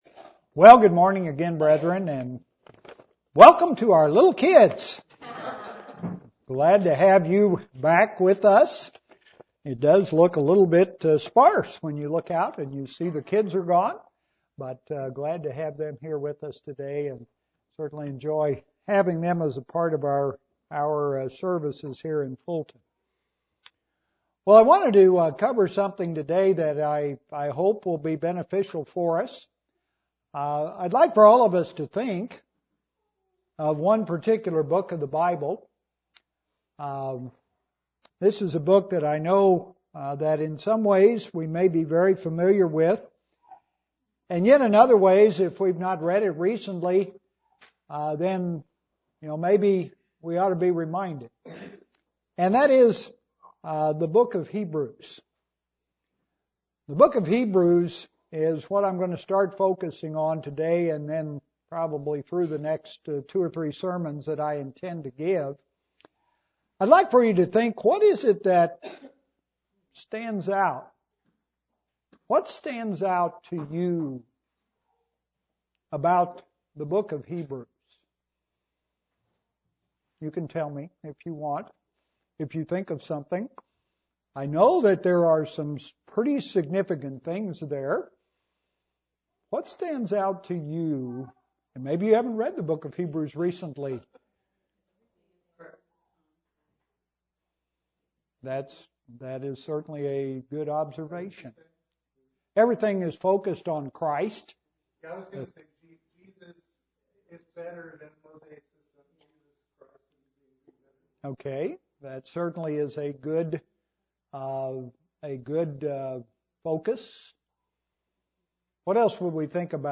Christ the High Priest UCG Sermon Transcript This transcript was generated by AI and may contain errors.